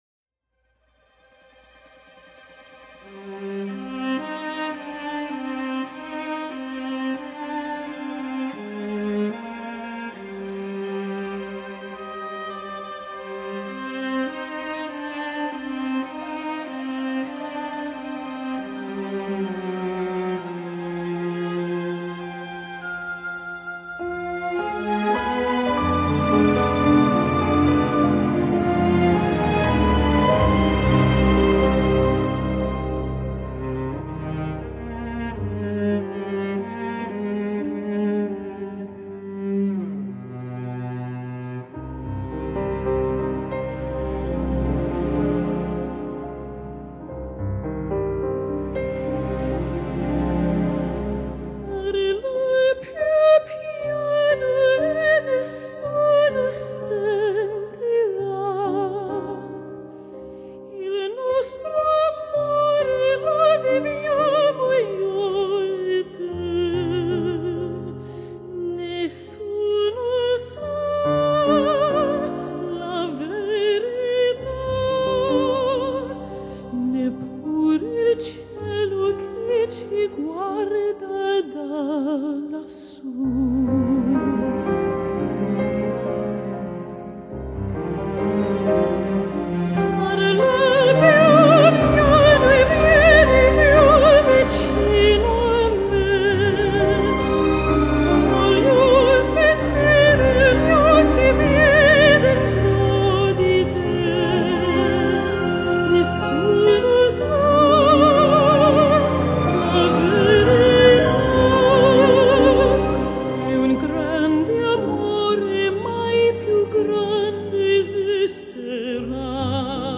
Genre: Classical, Pop, Vocal